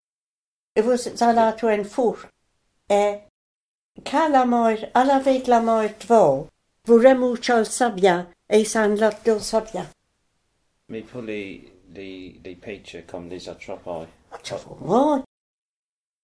Guernsey Patois
French Dialect